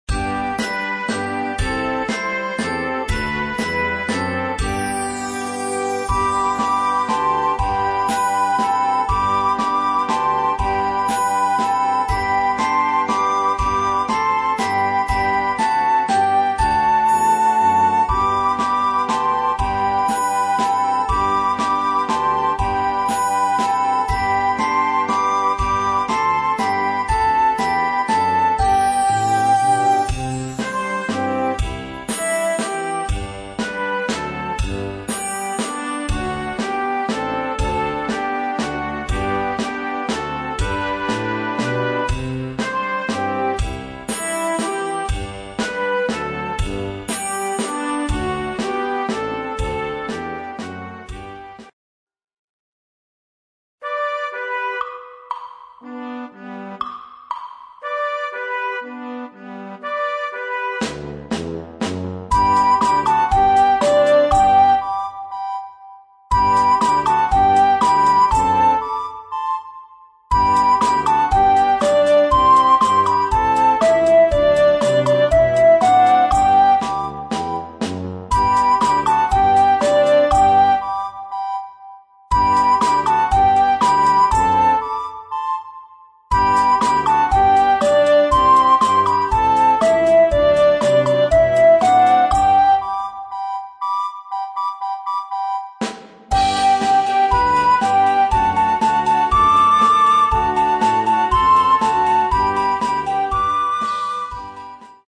für Blockflöte und Jugendblasorchester
Besetzung: Blasorchester